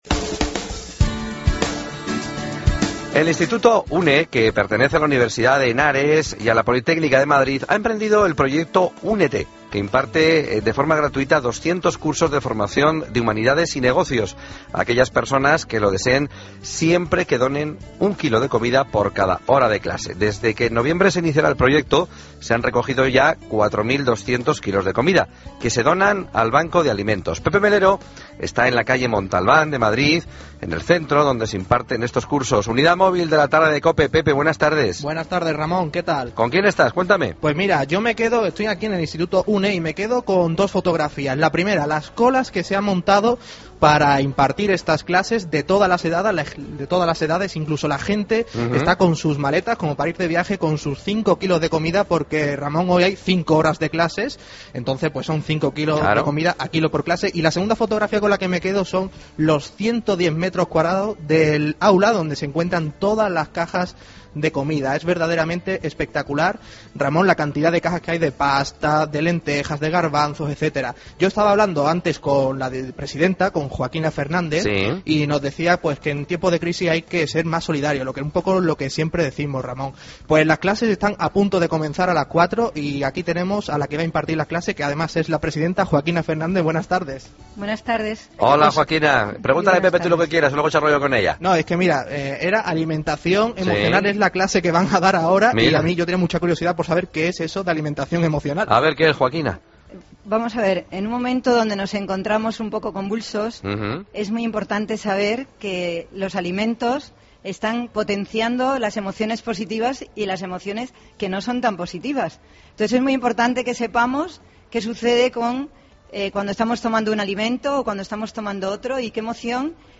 Entrevista
Dos alumnos nos cuentan su experiencia.